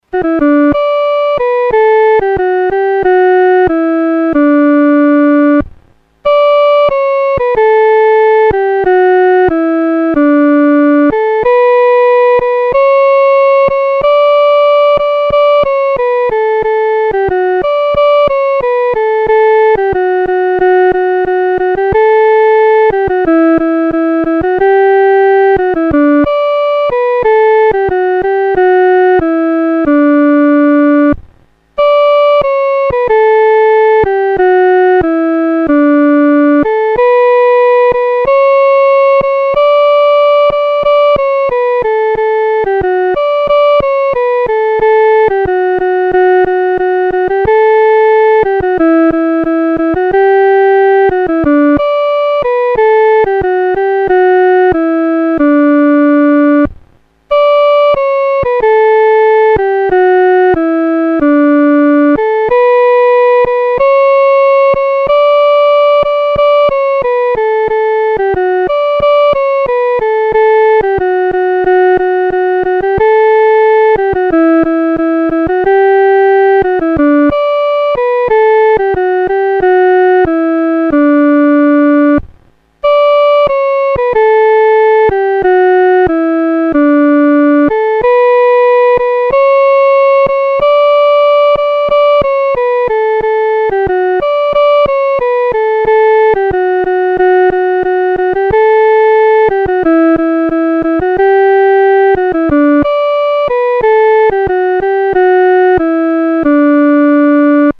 独奏（第一声）
普世欢腾-独奏（第一声）.mp3